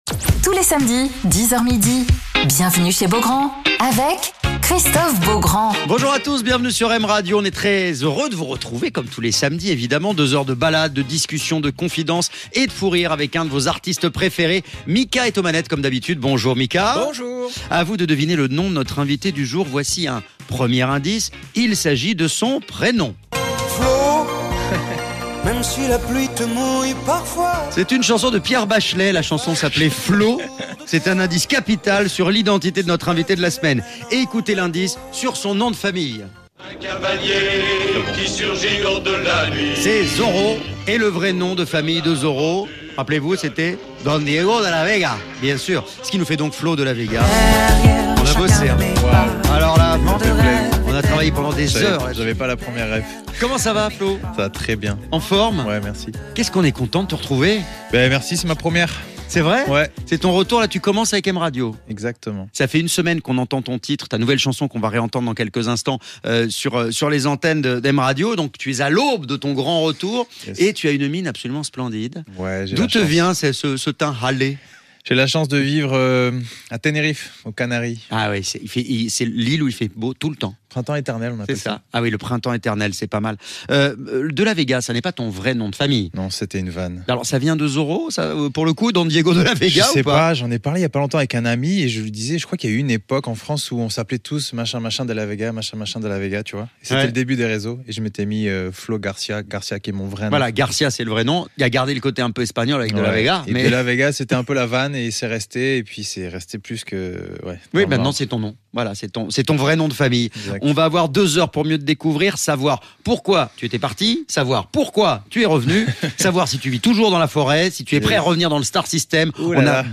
Alors qu'il est de retour avec un nouveau titre "Aimer les gens", après une belle reprise du titre d'Alain Souchon "La vie ne vaut rien", Flo Delavega est l'invité de Christophe Beaugrand sur M Radio !